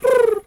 pigeon_2_call_11.wav